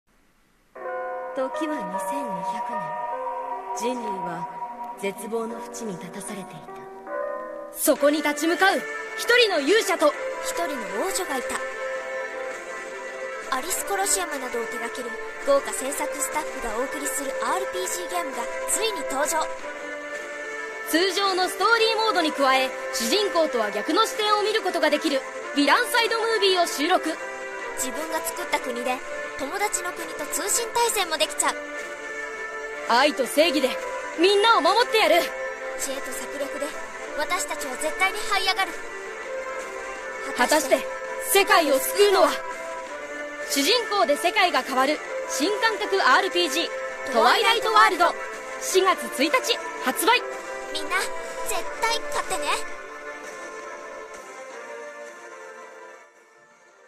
ゲームナレーション